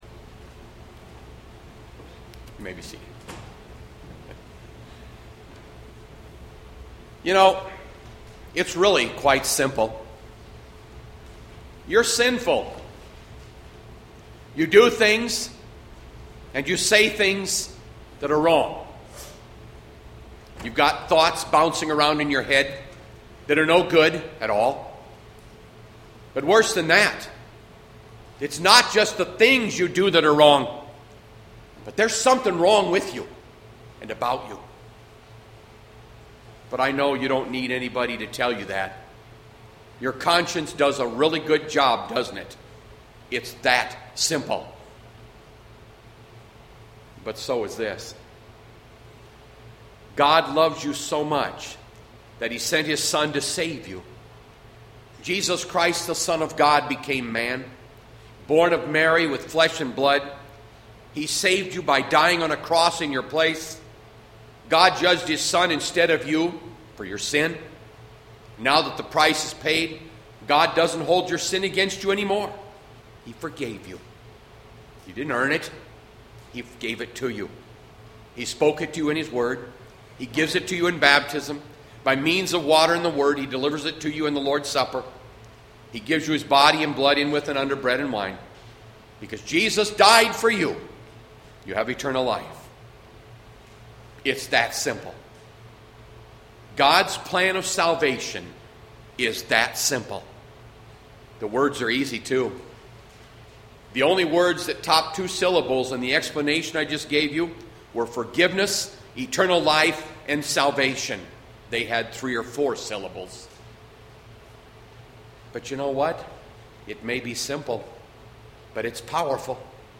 Bethlehem Lutheran Church, Mason City, Iowa - Sermon Archive Feb 9, 2020